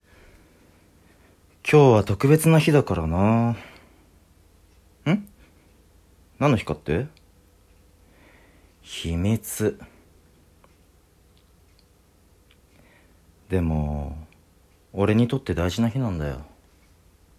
年下彼氏からの、ロマンチックで感動的なサプライズプロポーズを描いたシチュエーションボイスです。